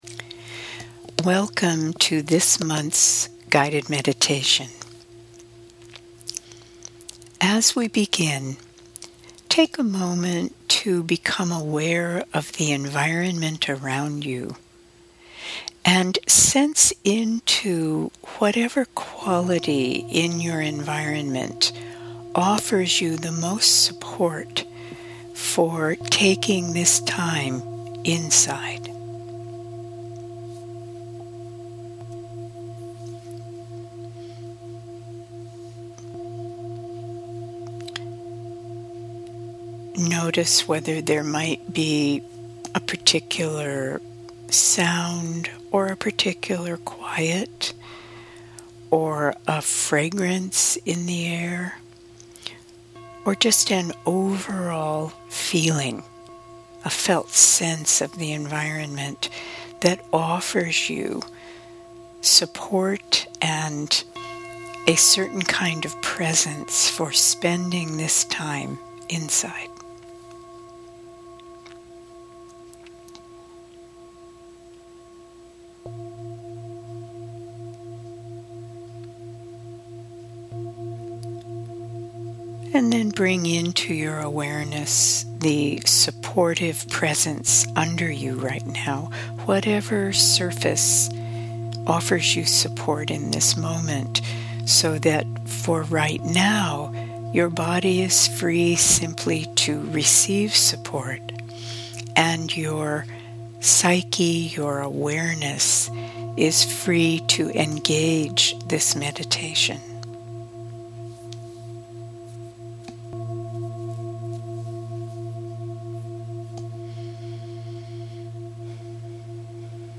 November 2018 Meditation